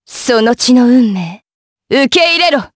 Lewsia_B-Vox_Skill6_jp.wav